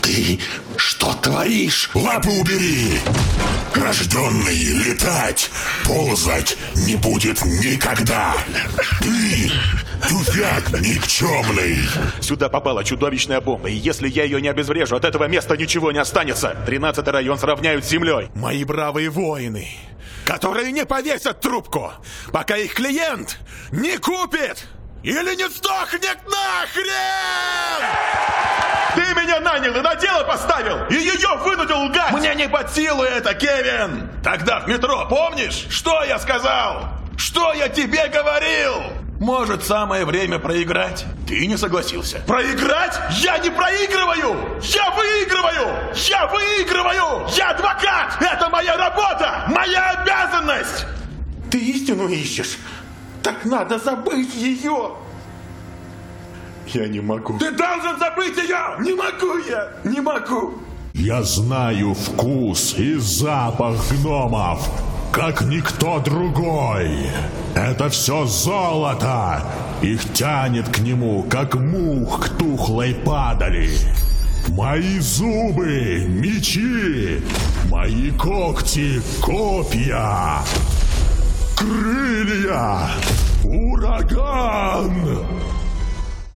• Кабина + Focusrite 2i2 3rd gen + dbx 286s + Rode NTG3b • Качественные кабели, тихое помещение, ничего не шумит.